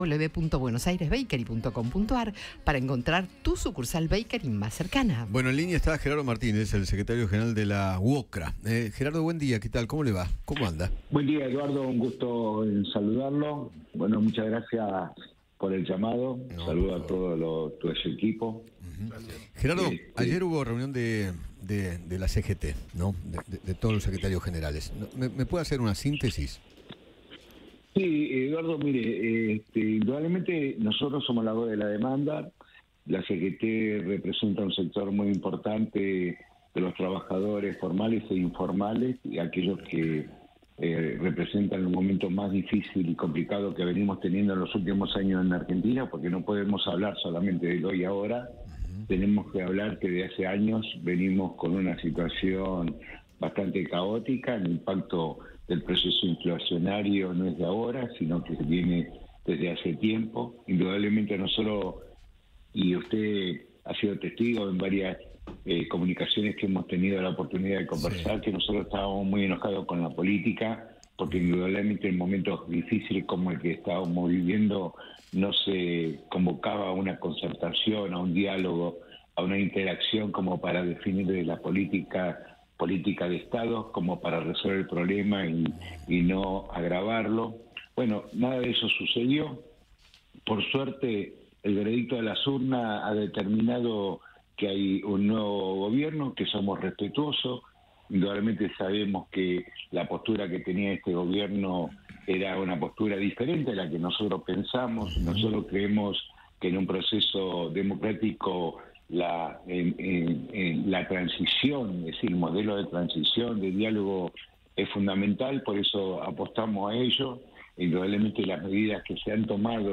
Gerardo Martínez, secretario General de la UOCRA, habló con Eduardo Feinmann sobre la reunión que mantuvo ayer la CGT y el impacto de las medidas económicas anunciadas por Luis Caputo.